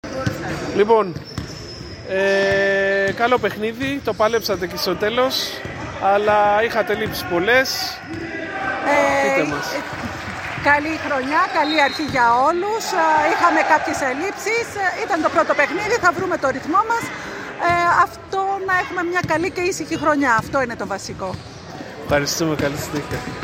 GAMES INTERVIEWS